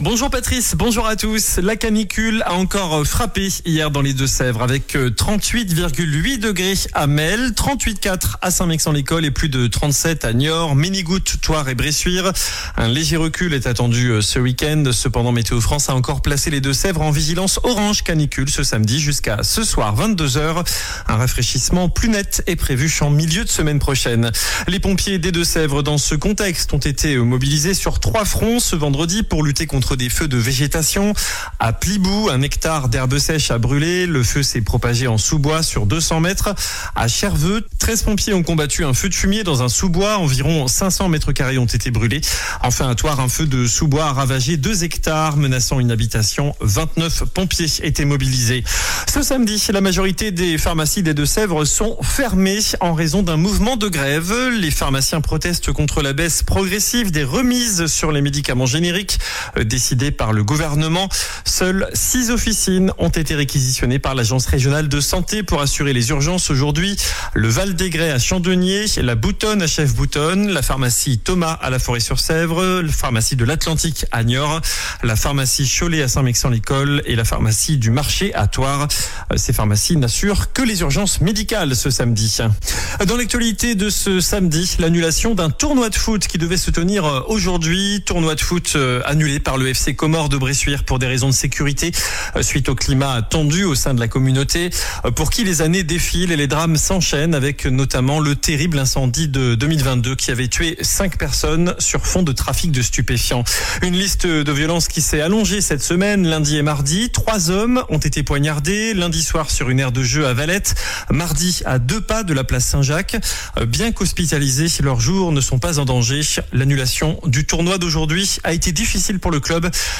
Trois hommes ont été poignardés cette semaine, vous entendrez dans ce journal l’inquiétude du maire de Bressuire qui cherche des solutions.